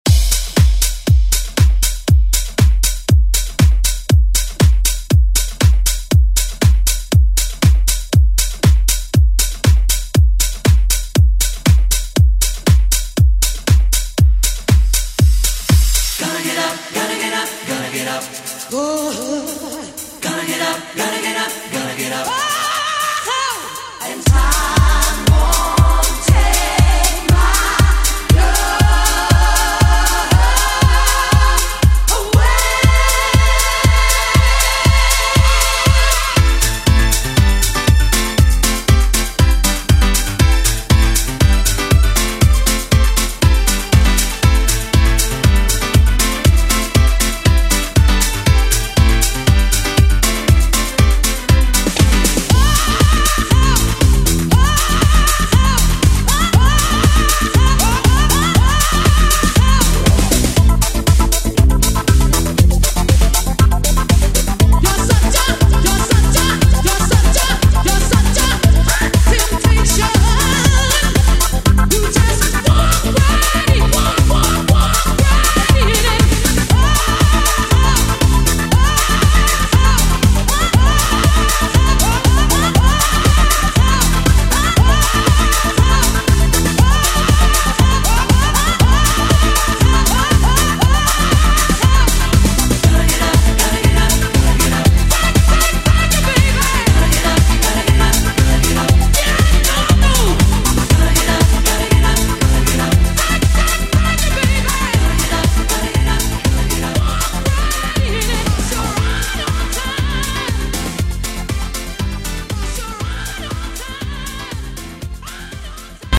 Electronic Pop House Music Extended ReDrum Clean 126 bpm
Genre: 90's
Clean BPM: 126 Time